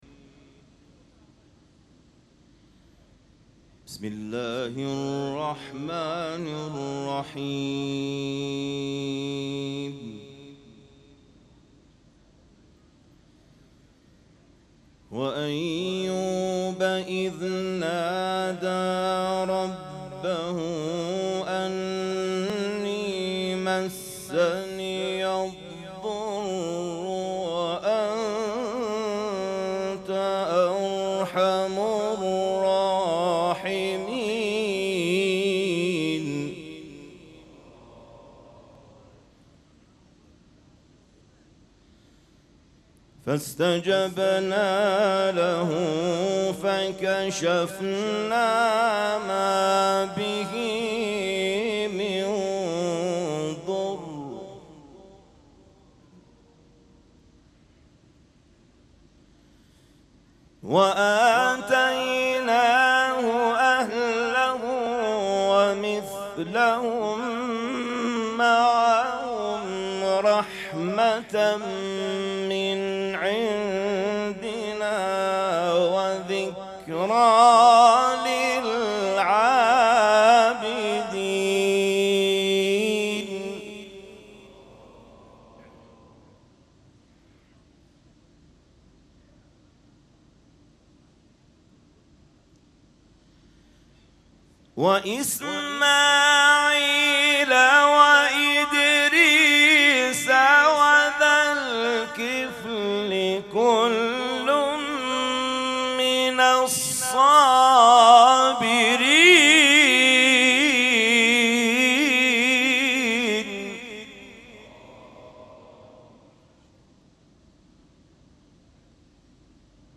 مراسم عزاداری شب دهم محرم الحرام ۱۴۴۷
قرائت قرآن